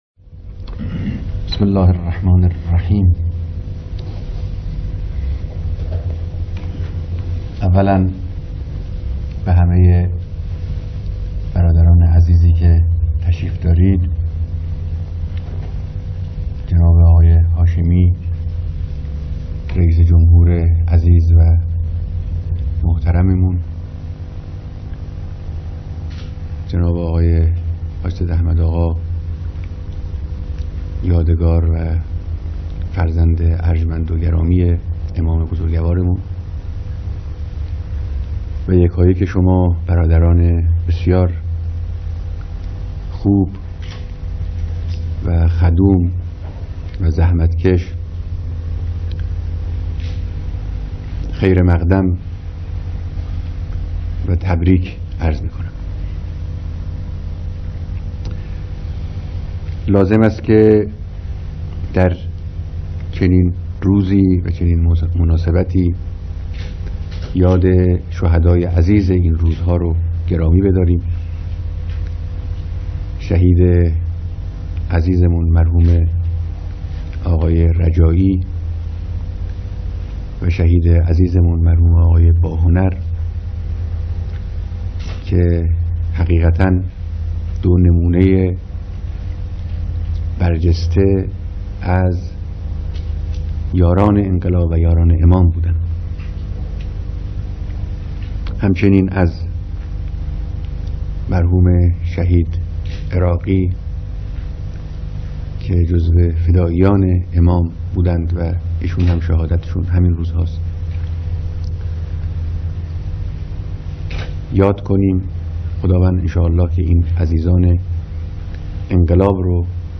بیانات رهبر انقلاب در دیدار اعضای هیأت دولت